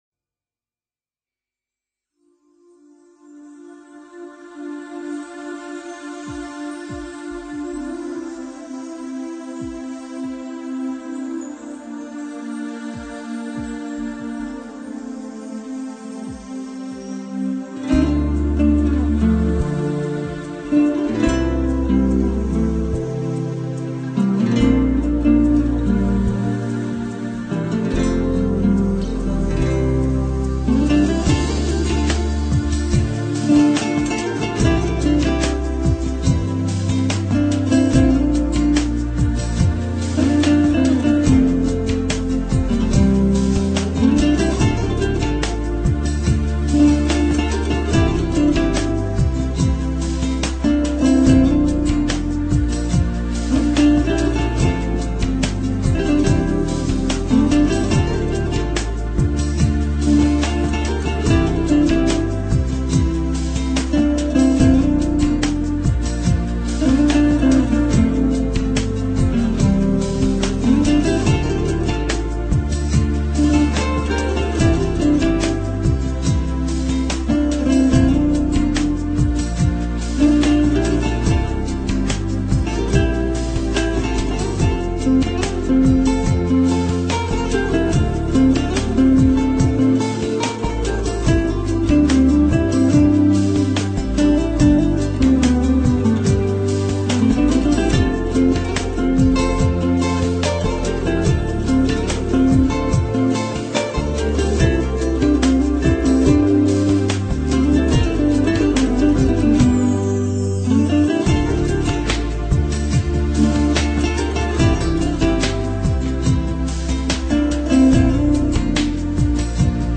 Spanish-romantic-music.mp3